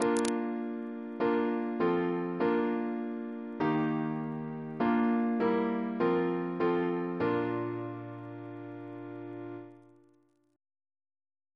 Single chant in B♭ Composer: William Crotch (1775-1847), First Principal of the Royal Academy of Music Reference psalters: ACB: 215; PP/SNCB: 3